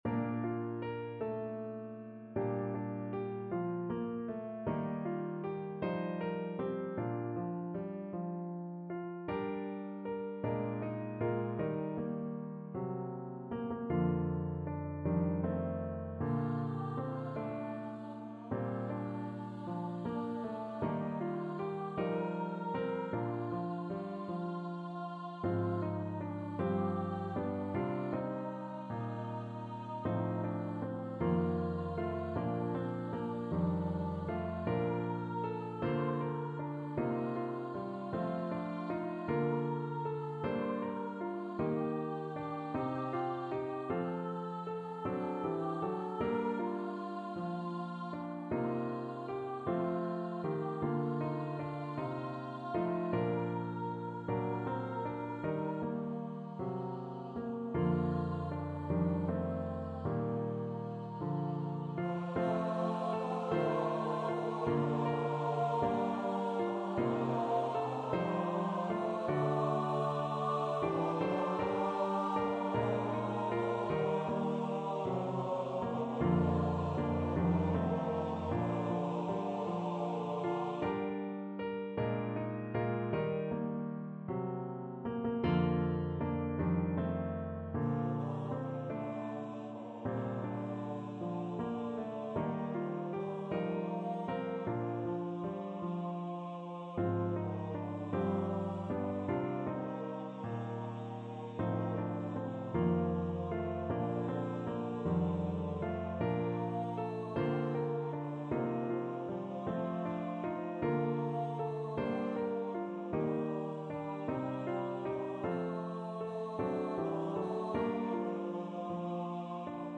SATB
Voicing/Instrumentation: SATB We also have other 17 arrangements of " His Eye Is On The Sparrow ".